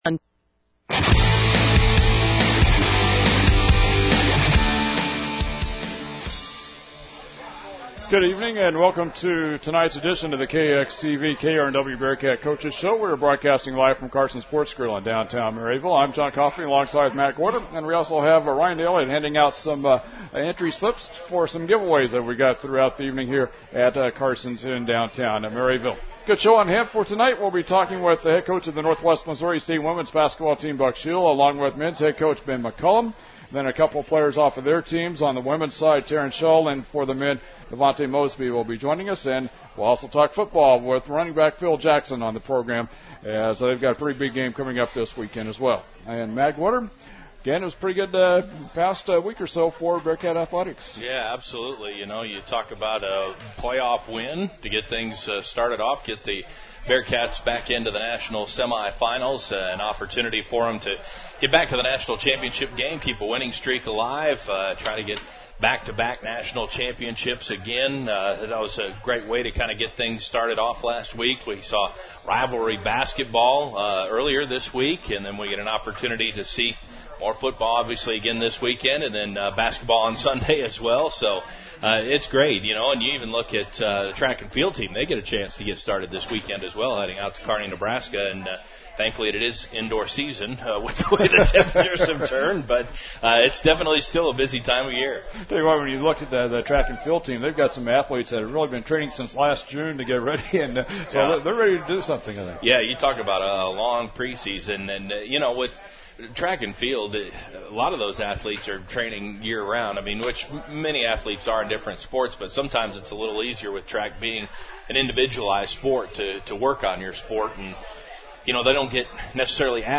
Coaches Show